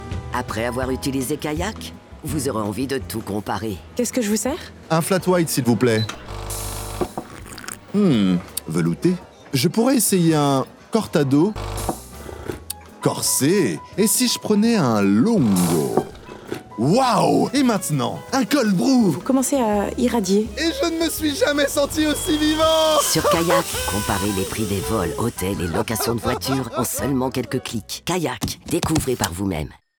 PUB Kayak - Coffee
- Basse